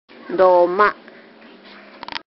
山口方言ライブラリ